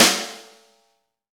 Index of /90_sSampleCDs/Club-50 - Foundations Roland/KIT_xExt.Snare 4/KIT_xExt.Snr 4dS
SNR XEXTS0UL.wav